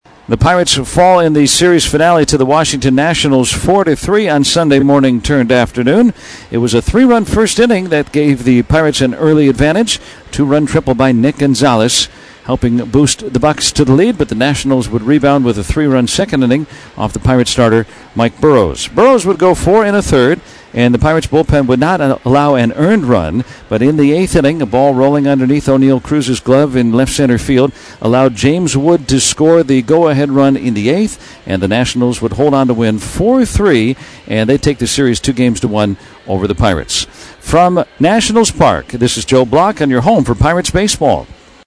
the recap of yesterday’s loss to the Nationals